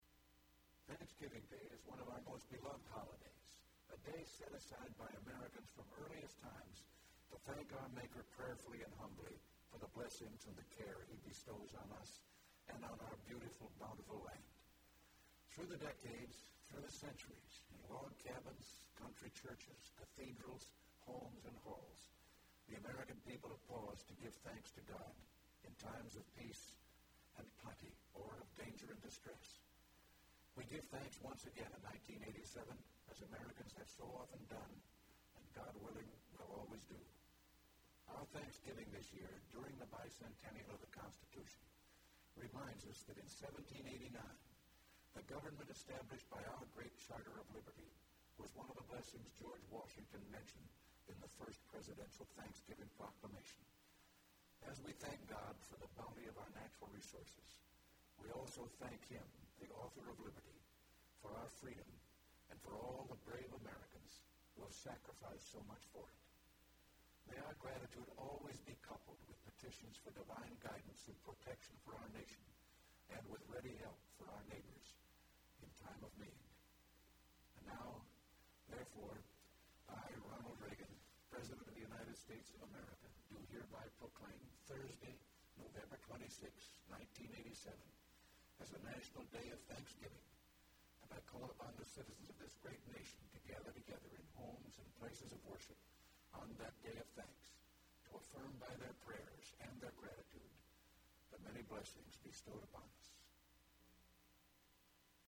Remarks of The President During Annual Thanksgiving Day Proclamation